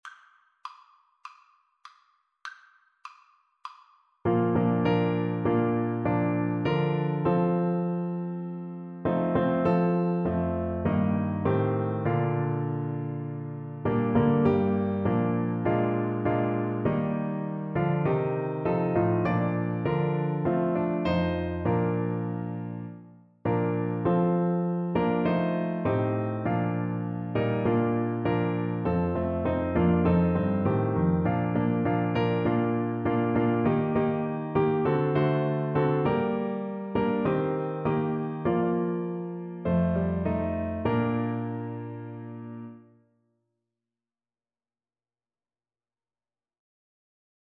4/4 (View more 4/4 Music)
Con Spirito